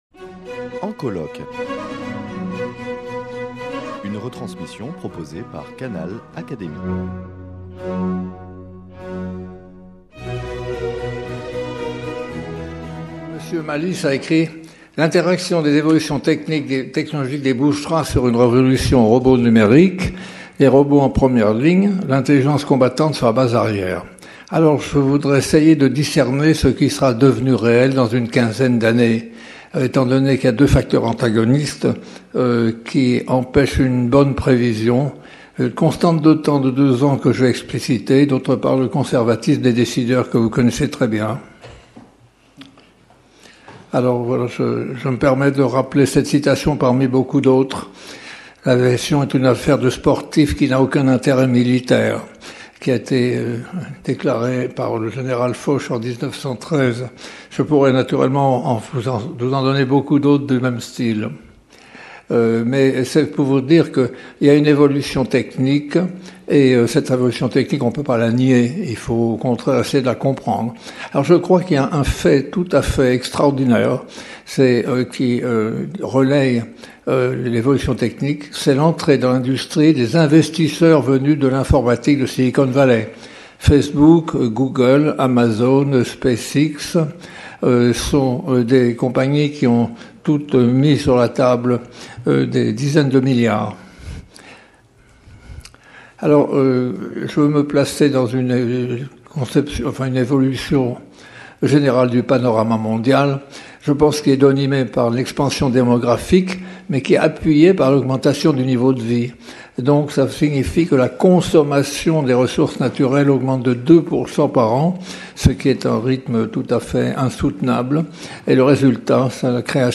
Journées d’étude « Guerre et technique » (4ème partie : Les mutations)Communication de Jacques Blamont, membre de l’Académie des sciences, prononcée le 15 octobre 2016 lors des journées d’étude « Guerre et technique » organisées dans le cadre du programme de recherche « Guerre et société » soutenu par la Fondation Simone et Cino del Duca et l’Académie des sciences morales et politiques.